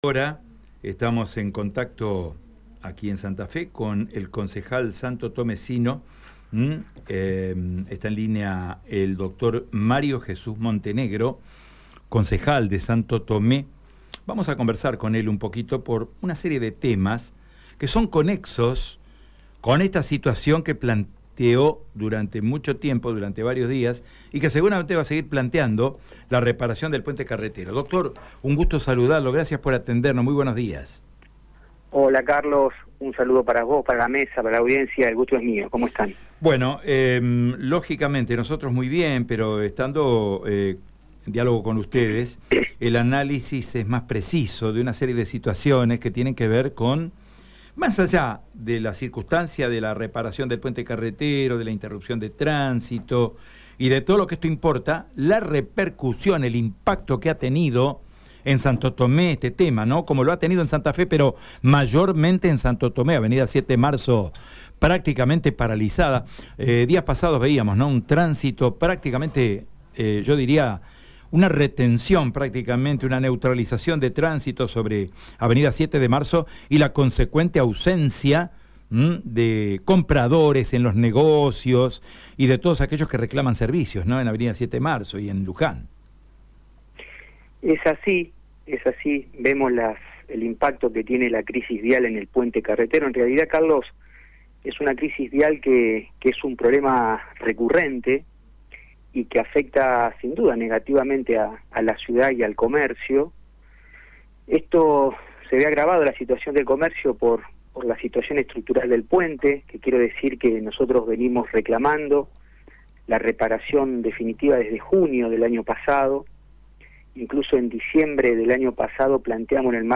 El concejal santotomesino, Dr. Mario Jesús Montenegro, dialogó con Radio EME en relación a la reparación del Puente Carretero. Cabe destacar que hoy es el primer día de funcionamiento del Puente Bailey colocado por encima de la antigüa estructura para permitir el paso de transporte de pasajeros.